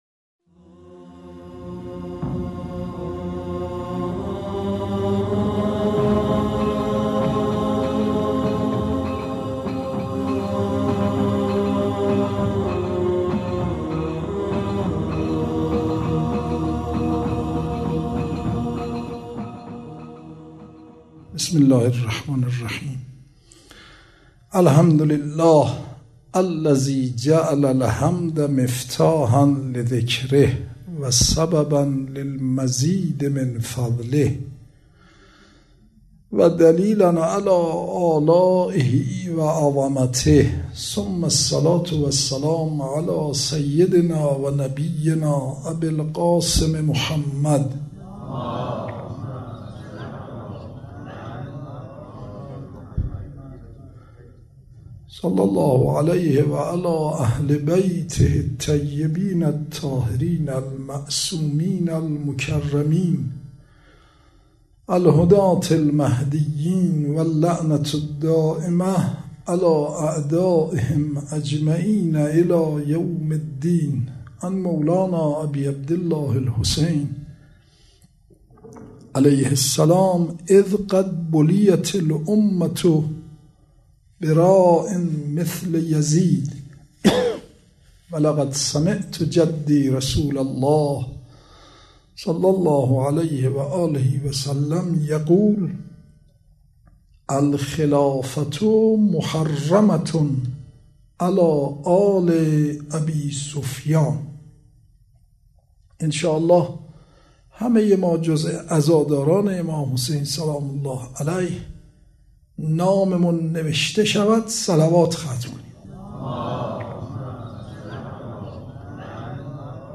مراسم ایام عاشورا 1436 هجری قمری روز دوم | سایت رسمی دفتر حضرت آيت الله العظمى وحيد خراسانى